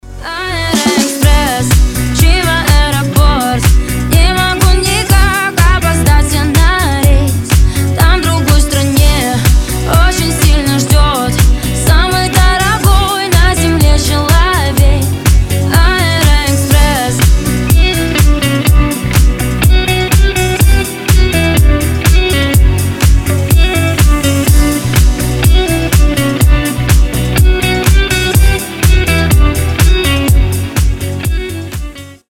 женский голос